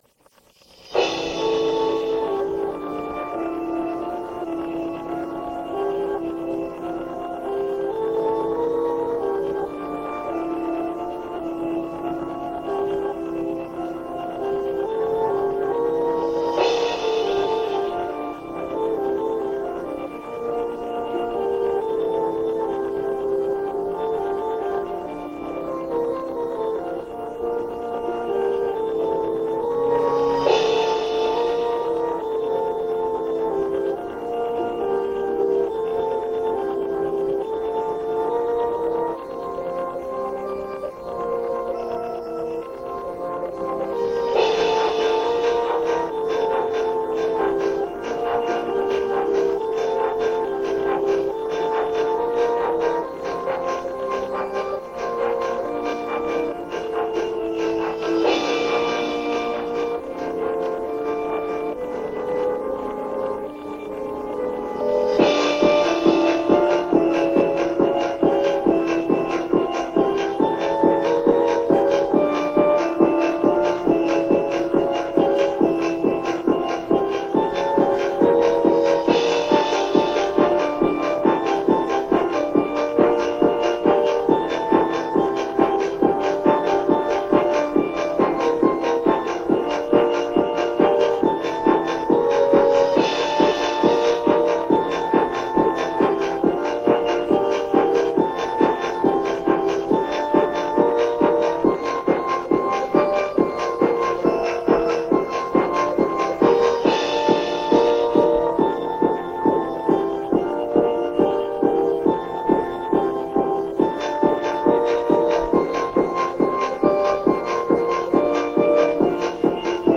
Dance Remake)file